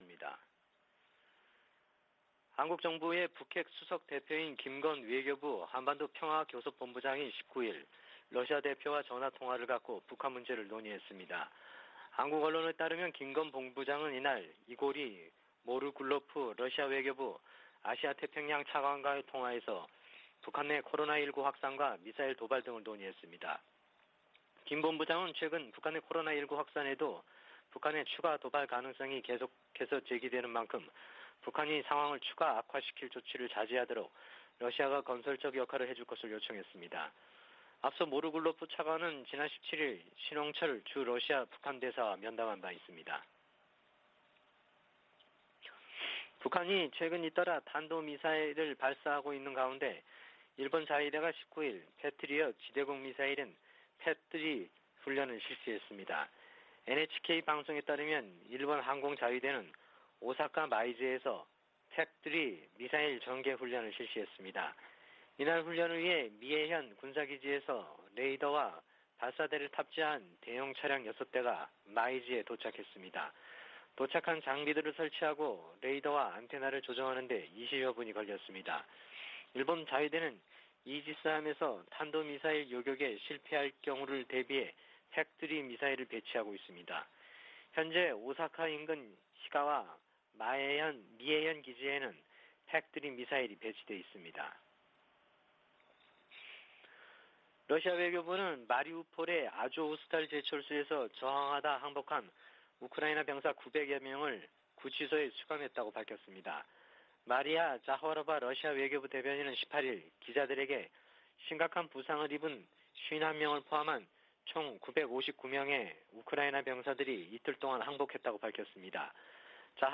VOA 한국어 '출발 뉴스 쇼', 2022년 5월 20일 방송입니다.